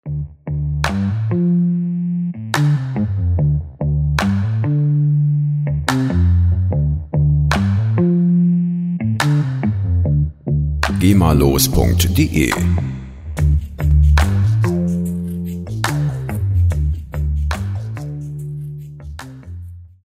Instrument: E-Bass
Tempo: 72 bpm